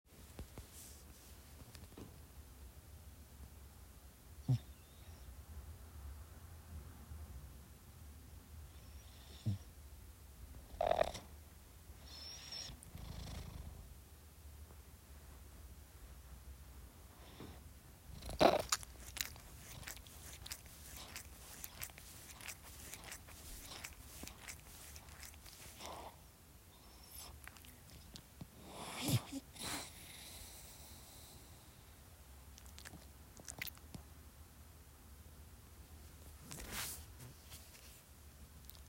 meow
Winning-snarfs-and-purrs13.m4a